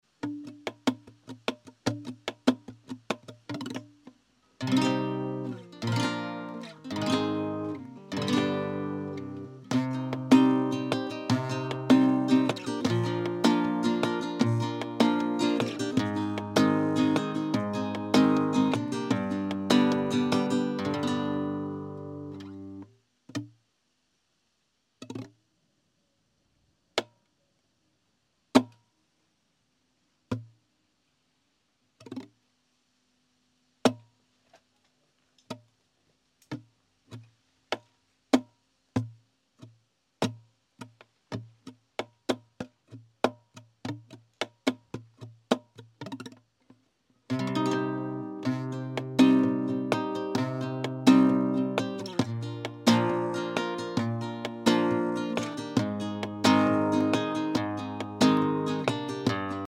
How To Play Spanish Rumba Sound Effects Free Download